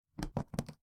stumble.wav